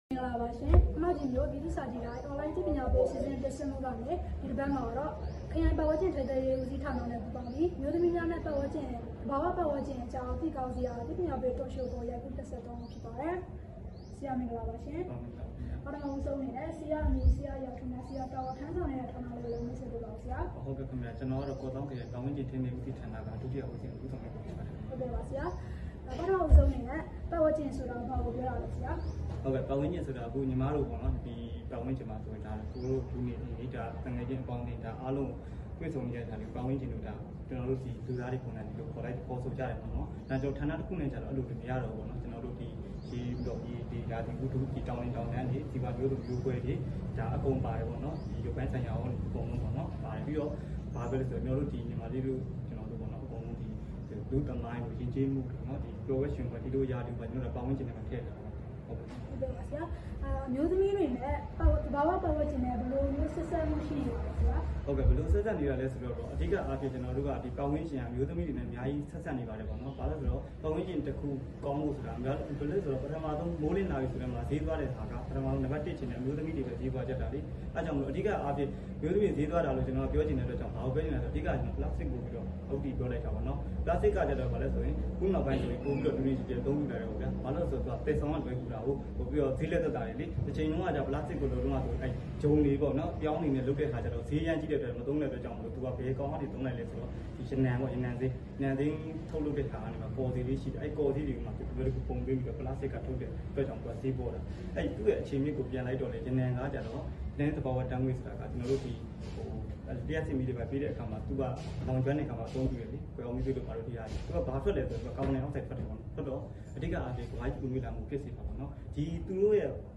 အမျိုးသမီးများနှင့်ပတ်ဝန်းကျင်အကြောင်းသိကောင်းစရာ အသိပညာပေးTalk Show
အမျိုးသမီးများနှင့်ပတ်ဝန်းကျင်အကြောင်းသိကောင်းစရာ အသိပညာပေးTalk Show ပြုလုပ် ခမောက်ကြီး ဒီဇင်ဘာ ၂၇